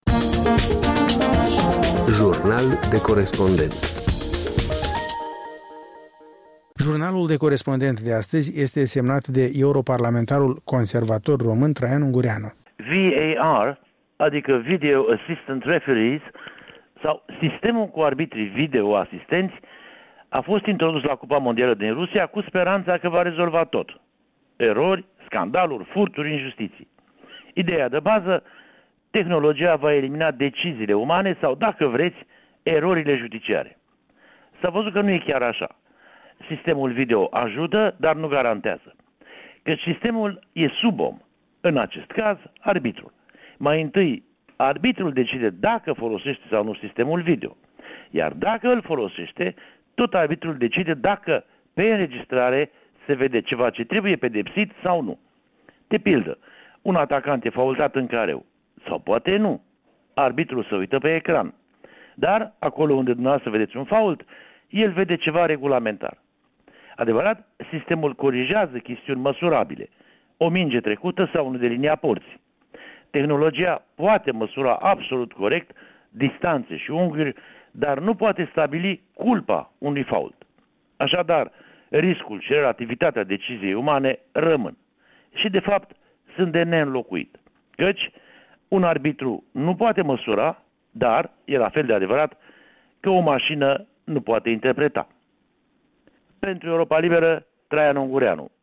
Jurnal de corespondent: Traian Ungureanu (Londra)